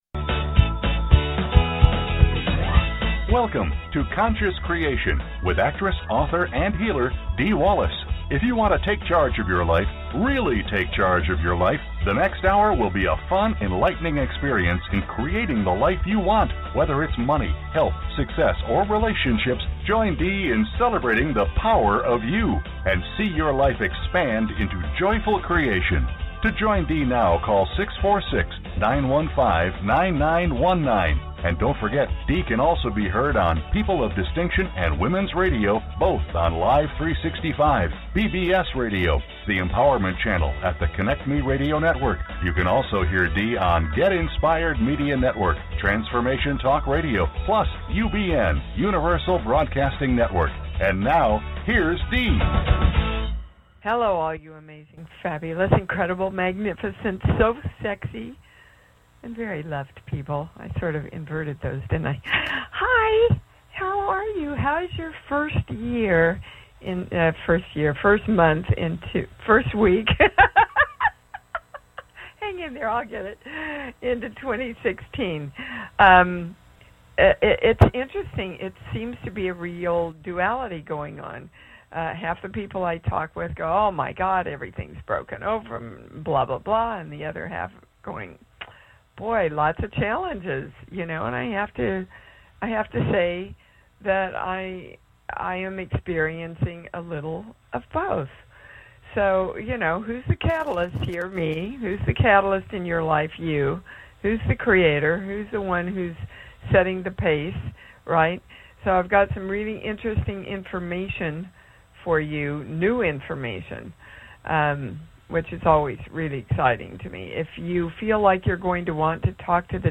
Subscribe Talk Show Conscious Creation Show Host Dee Wallace Dee's show deals with the latest energy shifts and how they correspond with your individual blocks.